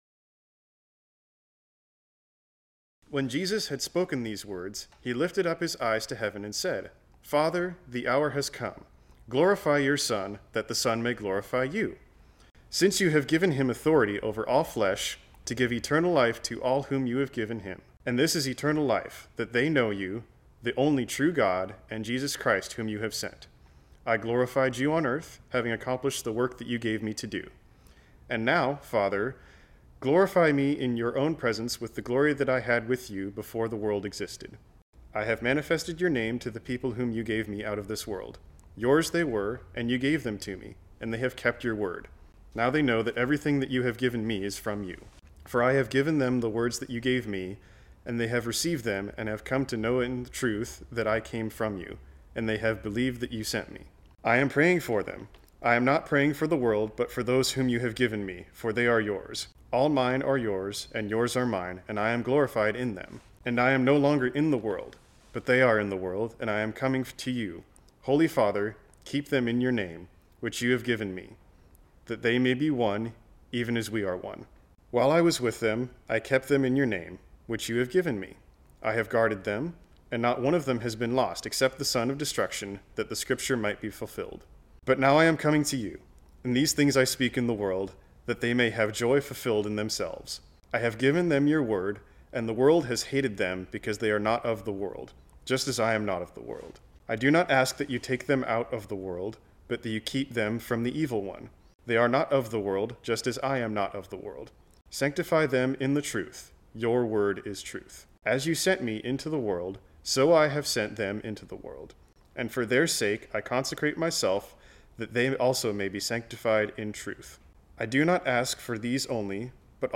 sermon
This sermon was originally preached on Sunday, July 5, 2020.